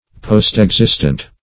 Search Result for " postexistent" : The Collaborative International Dictionary of English v.0.48: Postexistent \Post`ex*ist"ent\, a. Existing or living after.
postexistent.mp3